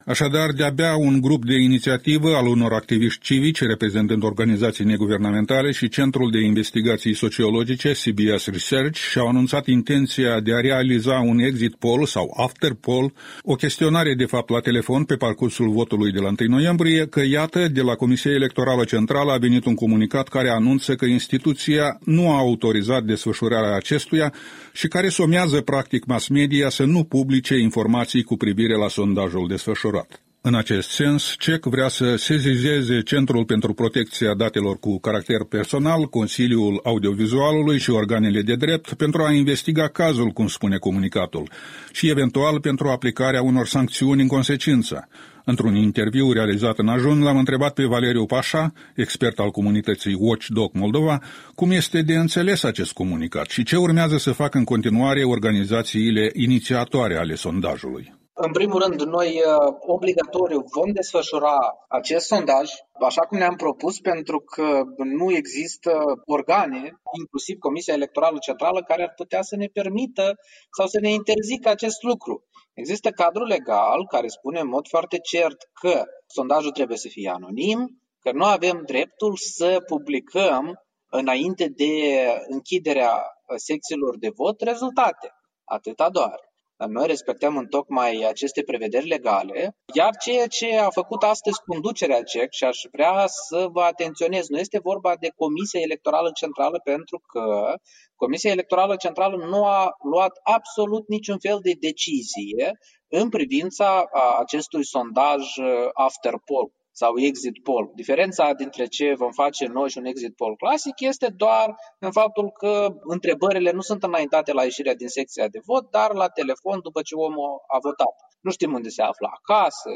Interviu matinal